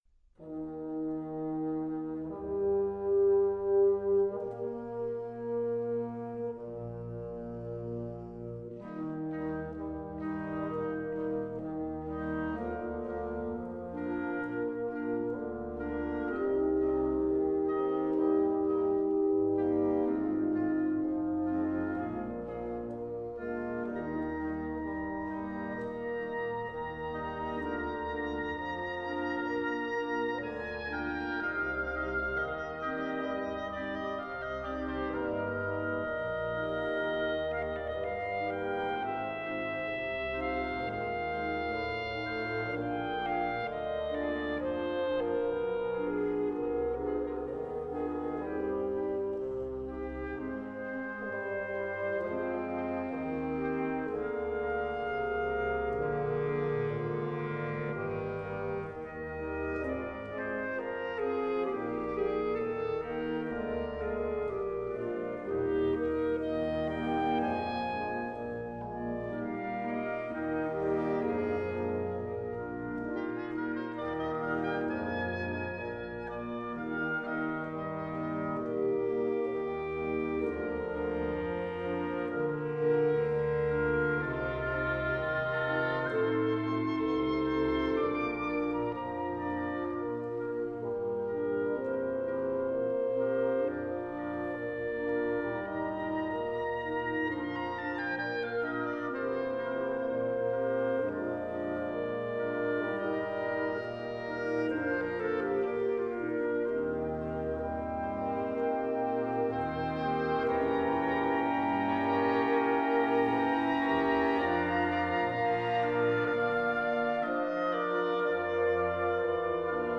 Luister naar “Gran Partita” – Adagio – W.A. Mozart door de solisten van de Koninklijke Muziekkapel der Gidsen [mp3-bestand]